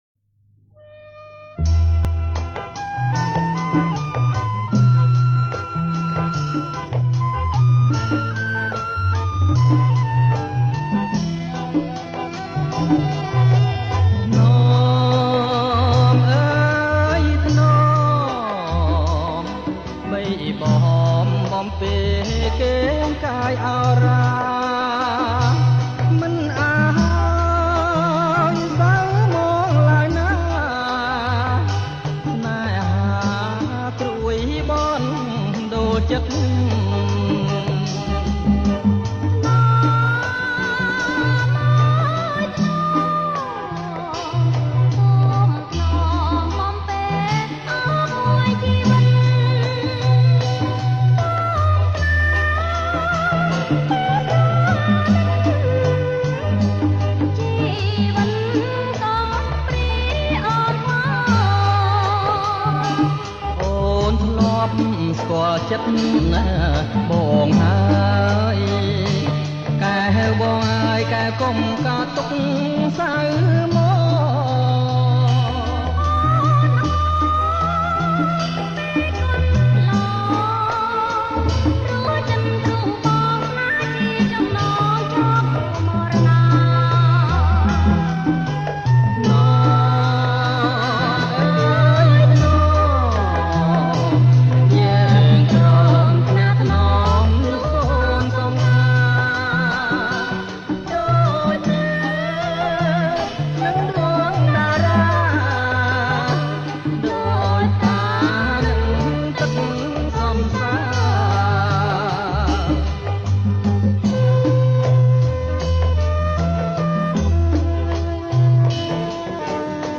ប្រគំជាចង្វាក់